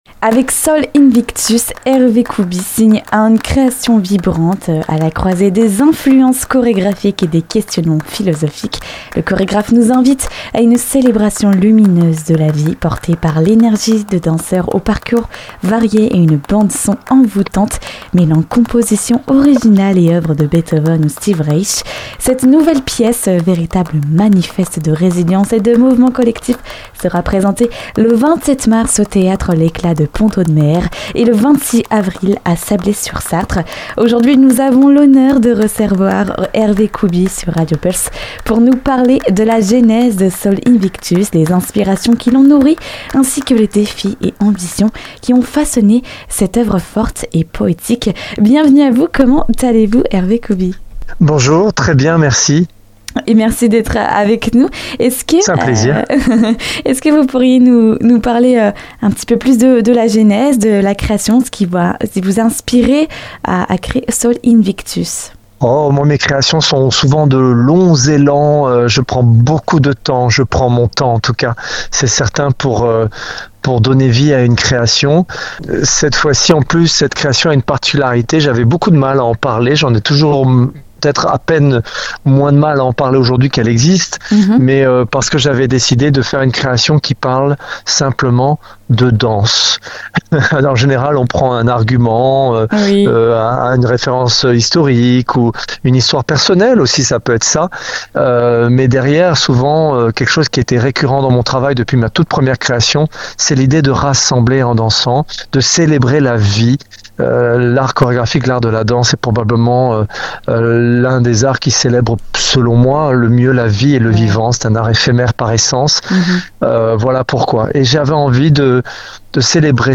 Nous avons le plaisir d'accueillir Hervé Koubi, chorégraphe. Il est avec nous pour discuter de sa pièce chorégraphique, “Sol invictus” et qui d’ailleurs passera le 26 avril à Sablé sur Sarthe. Pour en savoir plus sur cette pièce chorégraphique inspirante et philosophique, écoutez l'interview complète avec Hervé Koubi.